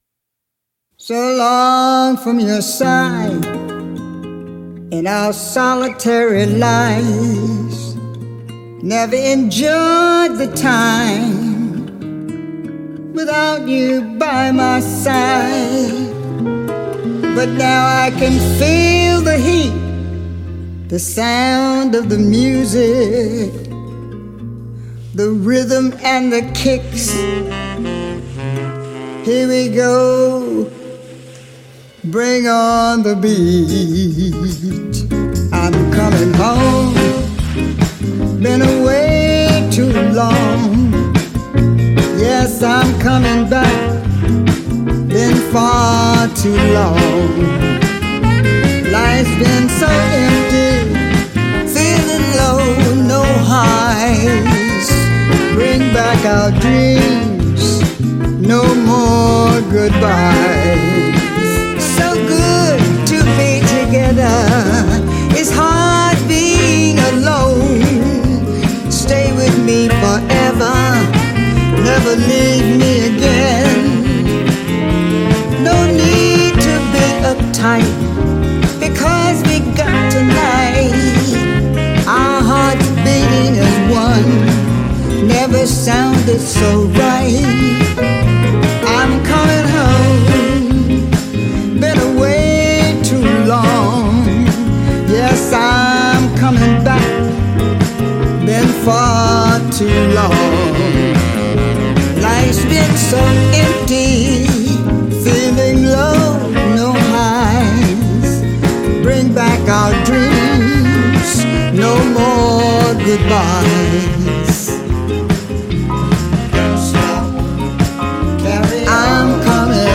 Post-Punk, Reggae, Pop